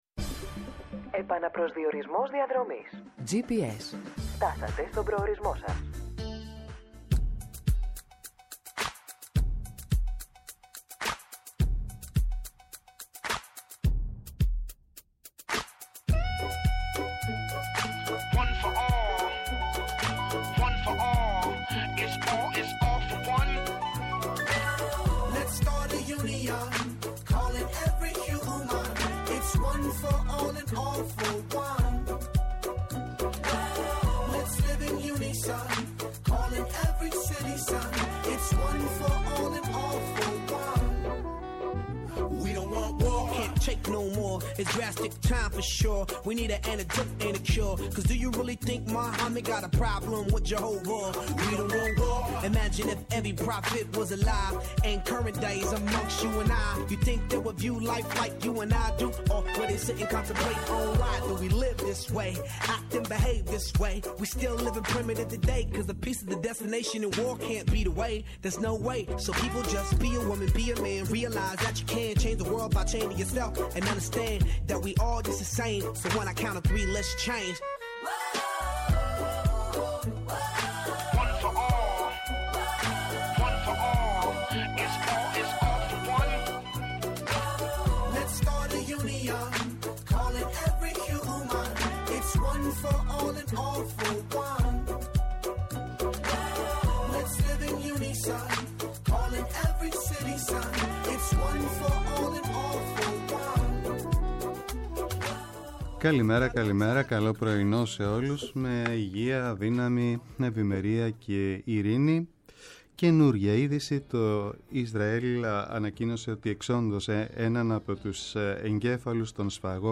ΠΡΩΤΟ ΠΡΟΓΡΑΜΜΑ